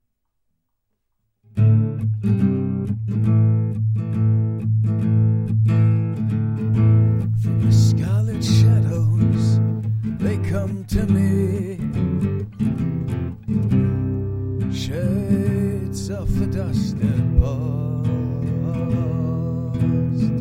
A Pirate remembers (full song - 138bpm).mp3